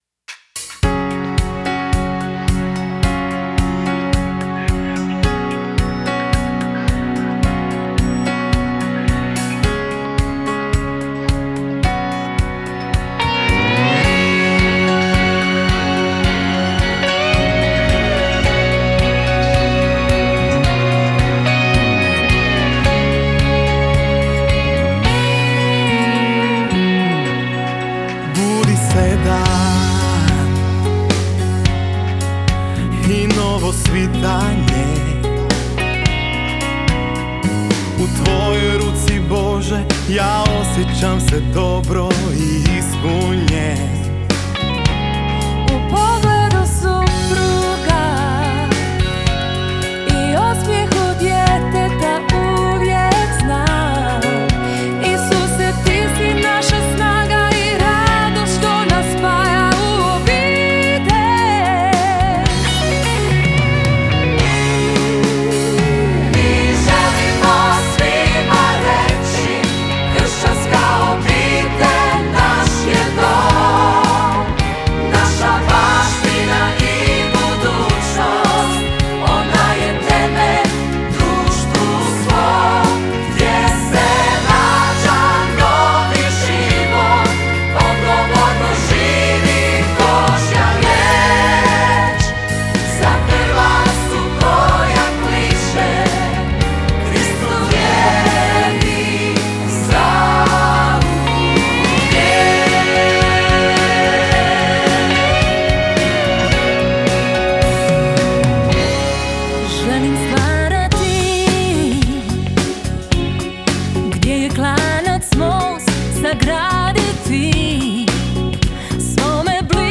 ponovnog snimanja u studiju